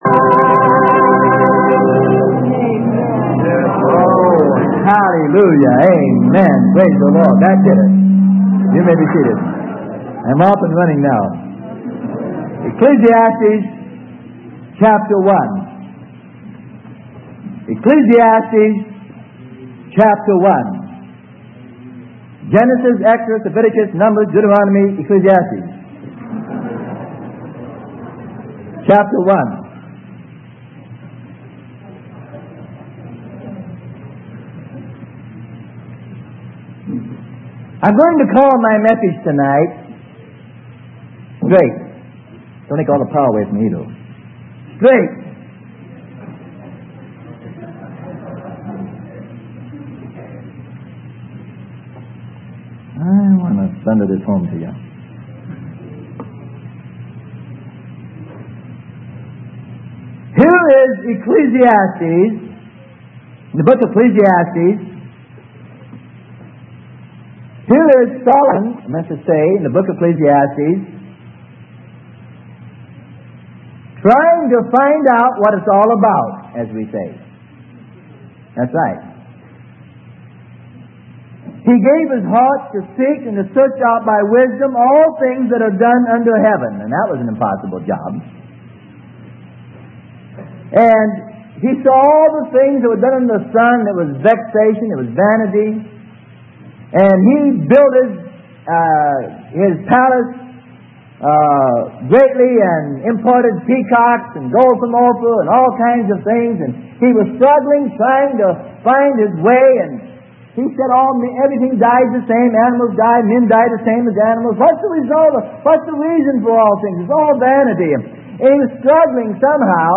Sermon: Straight (cont from tape SM-161-1) - Freely Given Online Library